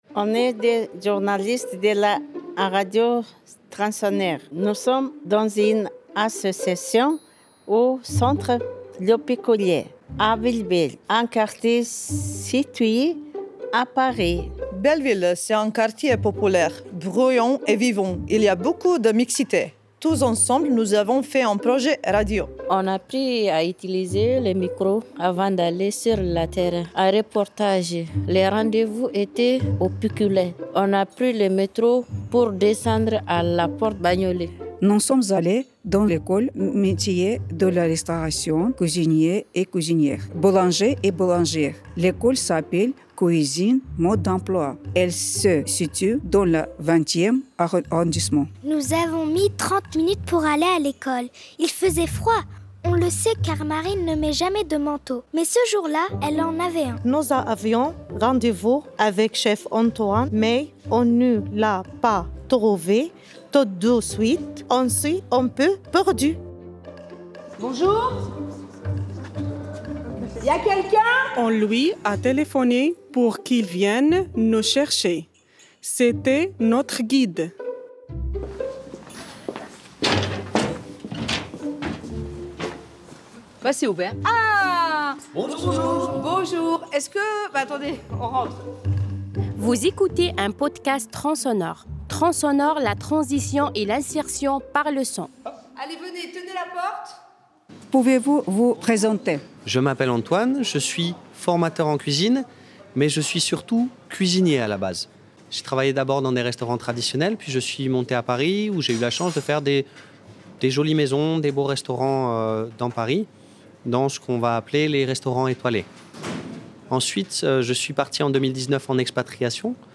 On est des journalistes de la radio transonore.
On a appris à utiliser le micro avant d’aller sur le terrain, en reportage.